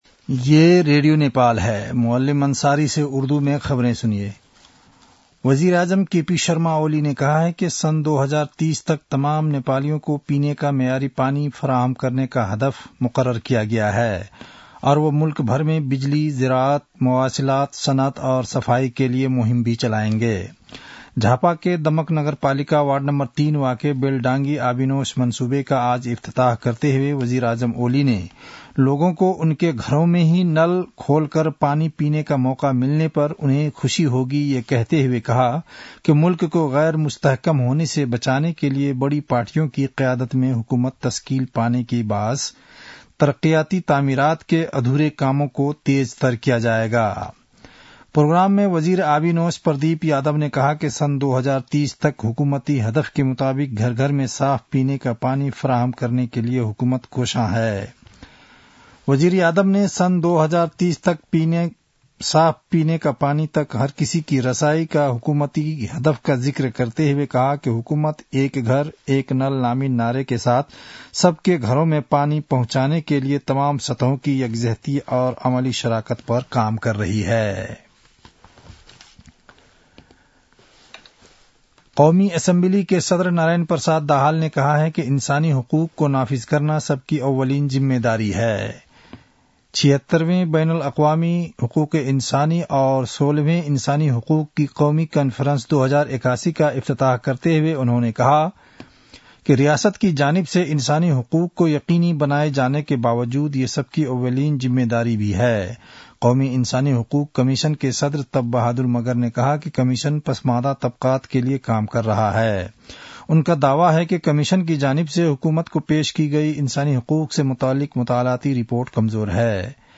उर्दु भाषामा समाचार : २५ मंसिर , २०८१